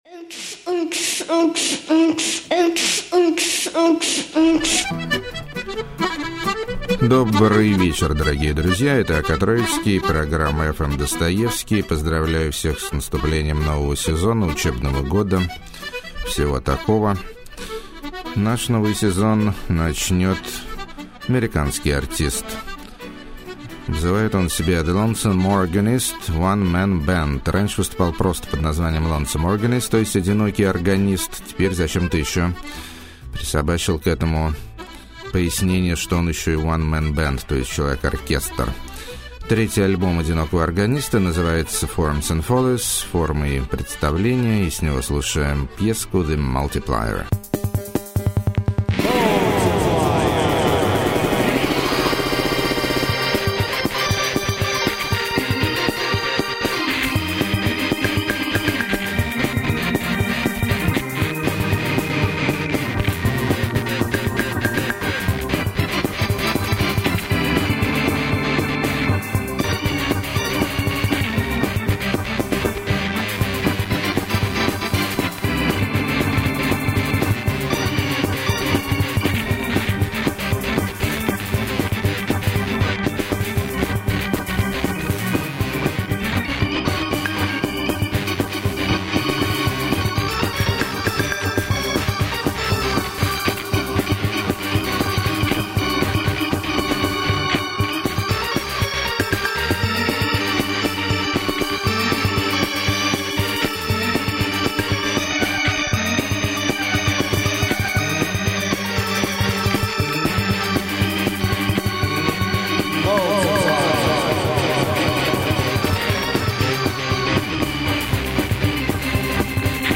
Post Sharmanka Circus Rock
Trip Goth
Dark Americana With A Jazzy Twist
Field Recordings Of Dead People Digitally Processed
Glam Punk